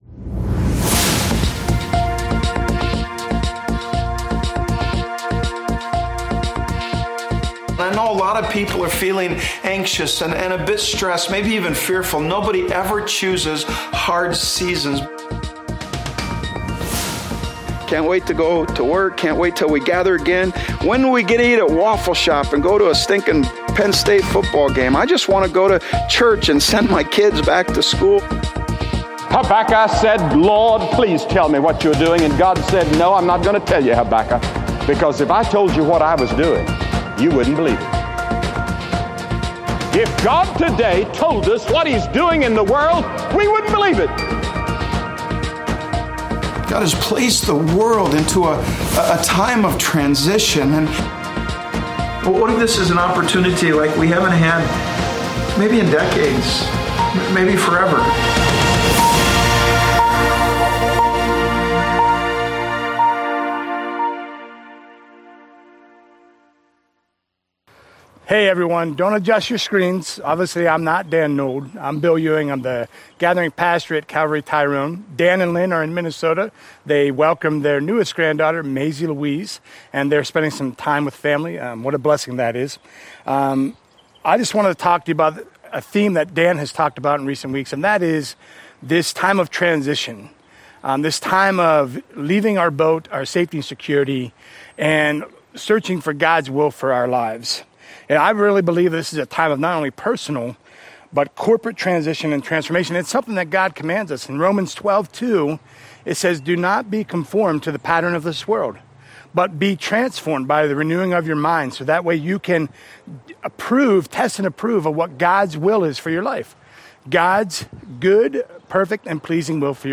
Trio of messages this weekend from Gathering Pastors